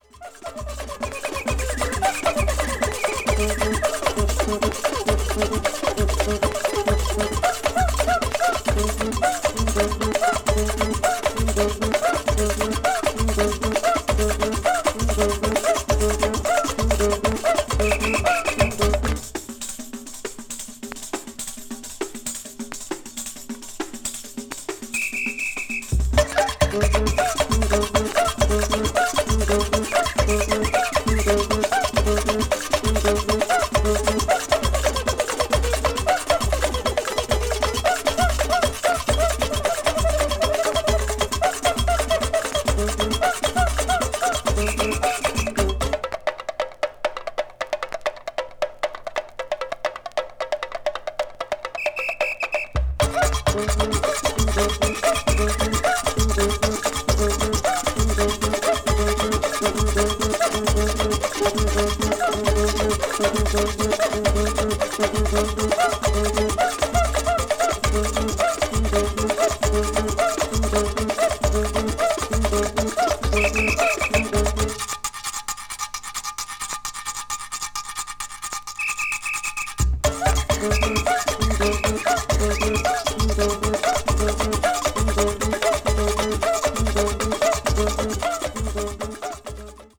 batucada   brazil   ethnic music   samba   world music